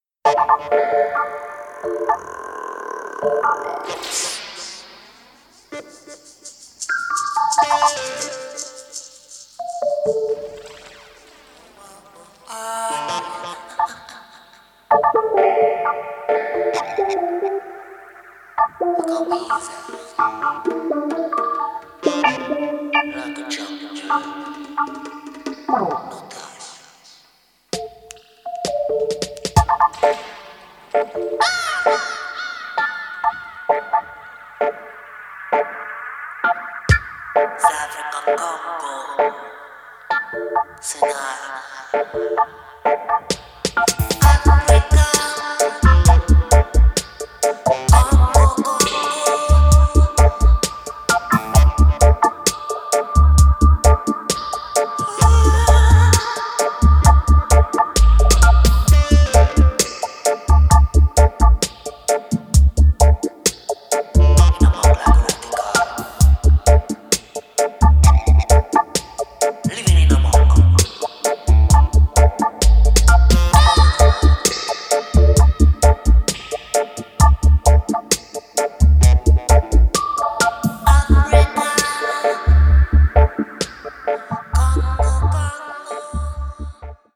無駄無く削ぎ落とされたモダンでミニマルな音響から浮かび上がるアーシーな郷愁。